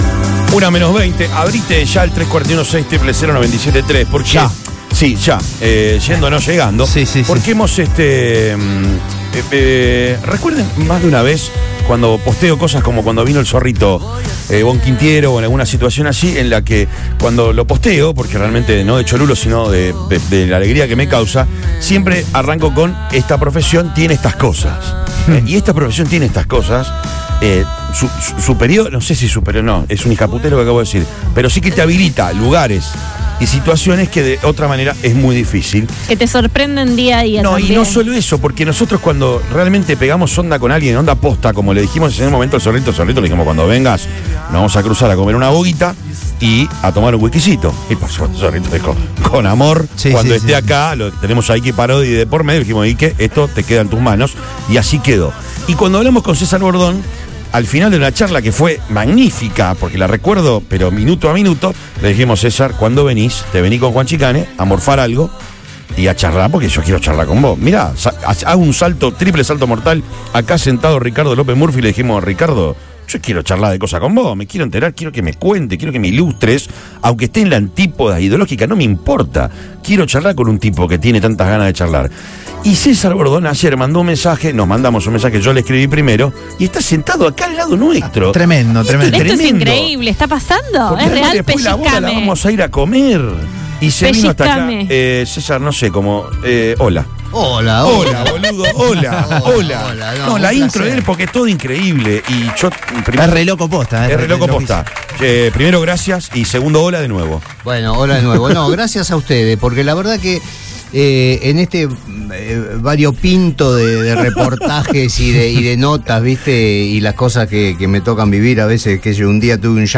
Aprovechando su presencia en Rosario, el actor pasó por los estudios de Radio Boing para dialogar en Lo Mejor de Todo. En una larga charla, el artista hizo un repaso por su carrera, se refirió al éxito de la serie de Luis Miguel y de Relatos Salvajes y contó en que consiste su nuevo trabajo.